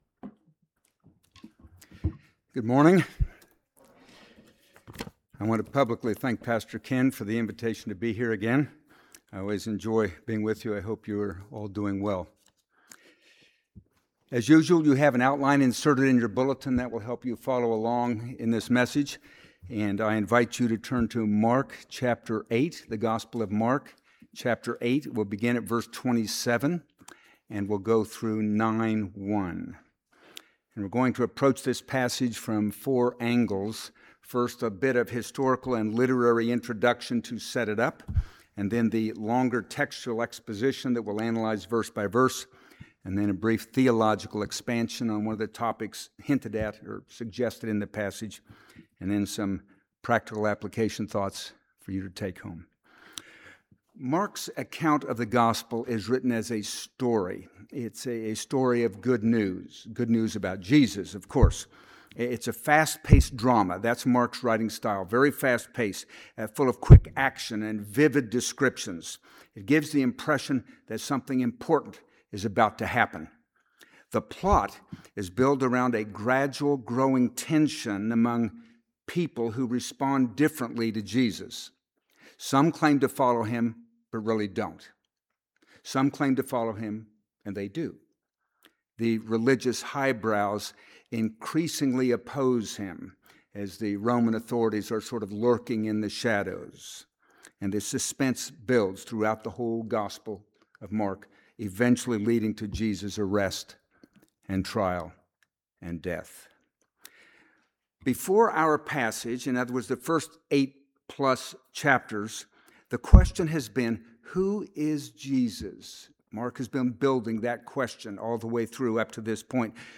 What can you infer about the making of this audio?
Passage: Mark 8:27-9:1 Service Type: Sunday AM